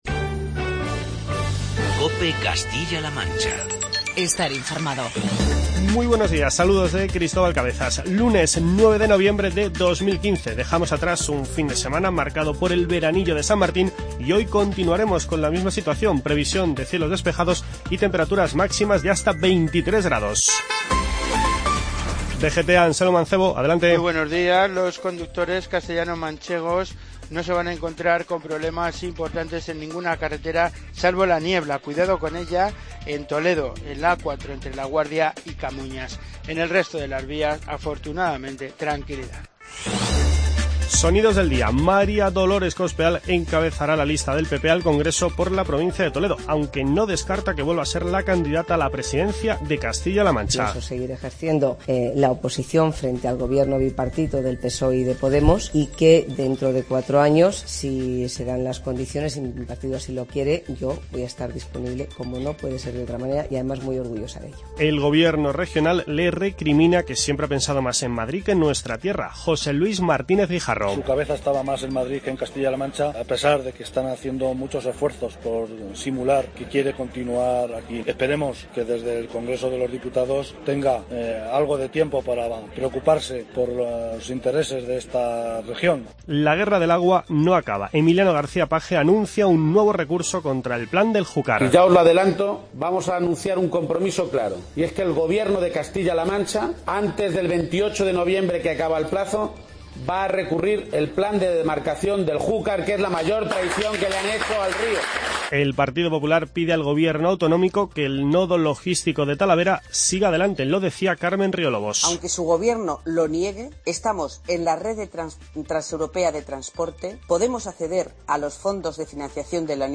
Informativo regional y provincial
Escucha las palabras de María Dolores Cospedal, José Luis Martínez Guijarro y Emiliano García-Page, entre otros testimonios de actualidad